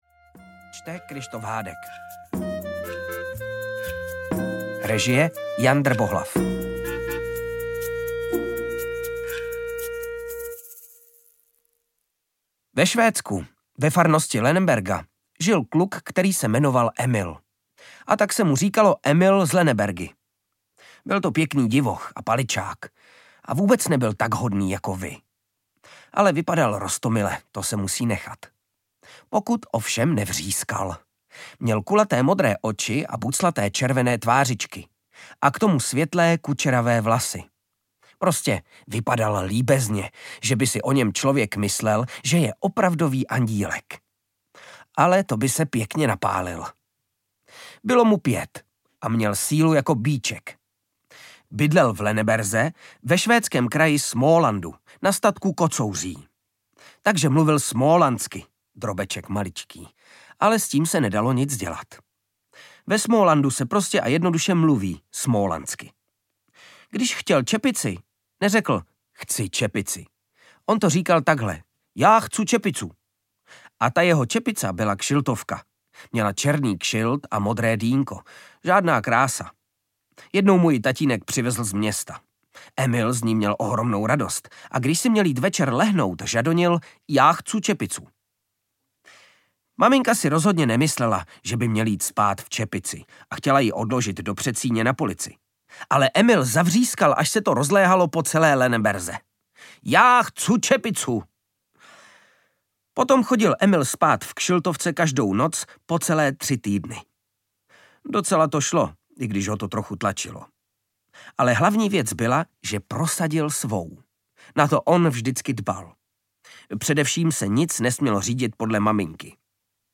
Emilovy skopičiny audiokniha
Ukázka z knihy
• InterpretKryštof Hádek